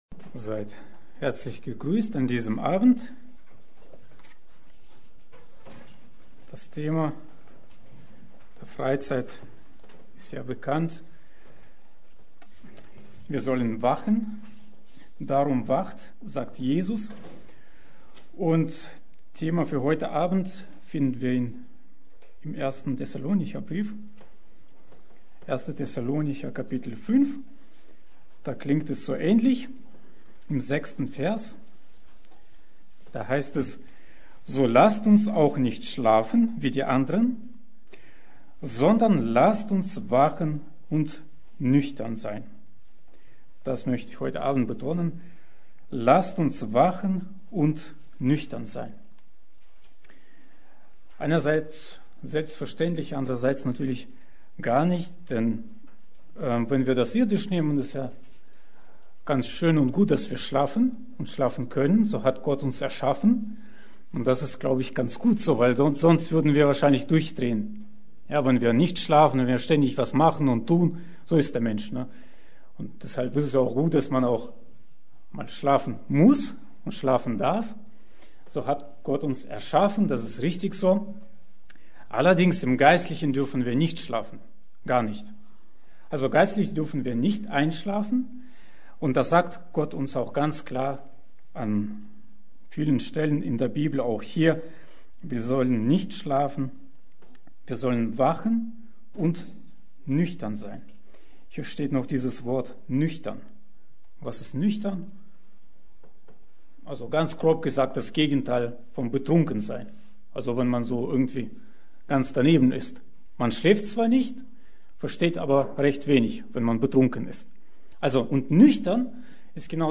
Predigt: Wachsam und nüchtern bleiben